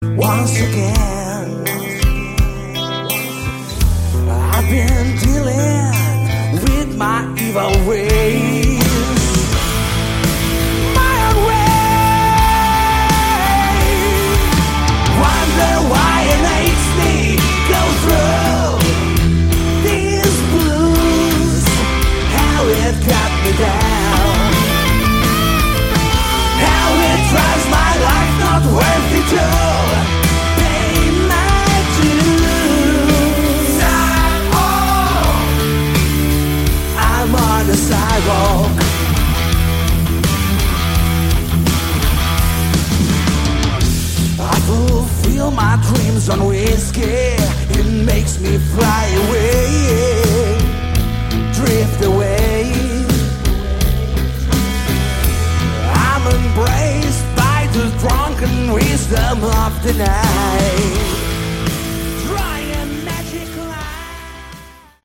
Category: Hard Rock
guitar, backing vocals
bass, backing vocals
drums, backing vocals